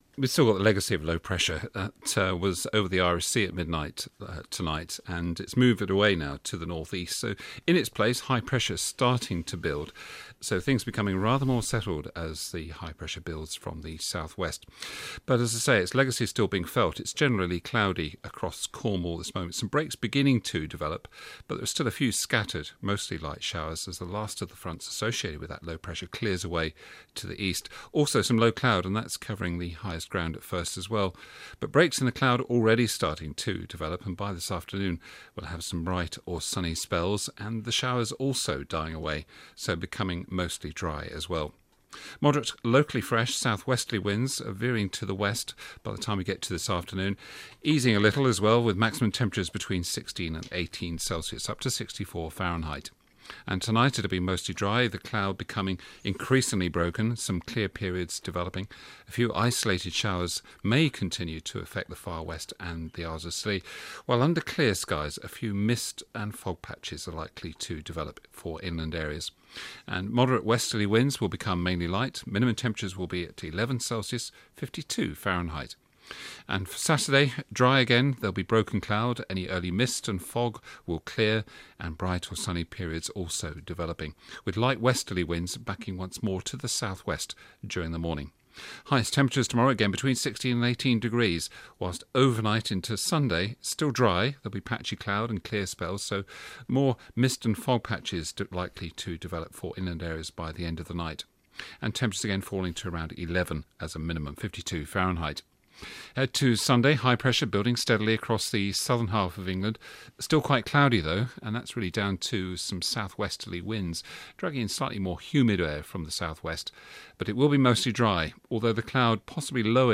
5 day forecast for Cornwall and Scilly from 8.15AM on 4 October